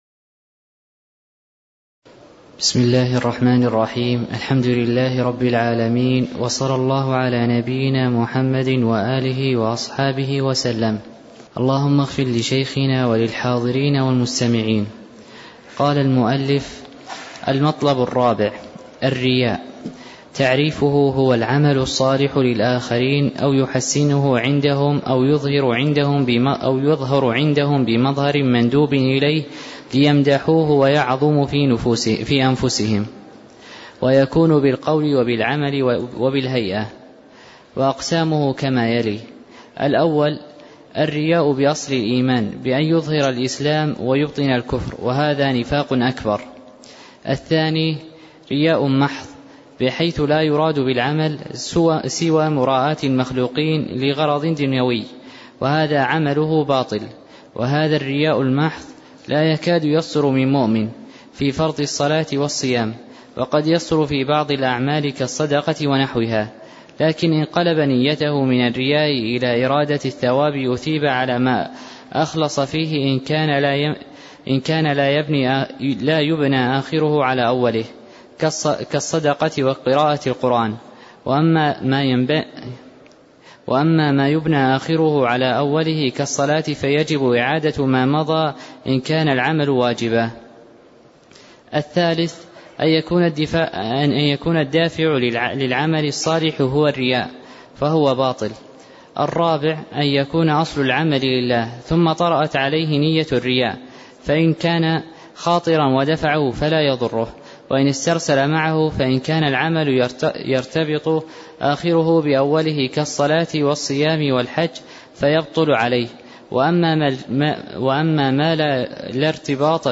تاريخ النشر ٢٢ شعبان ١٤٣٦ هـ المكان: المسجد النبوي الشيخ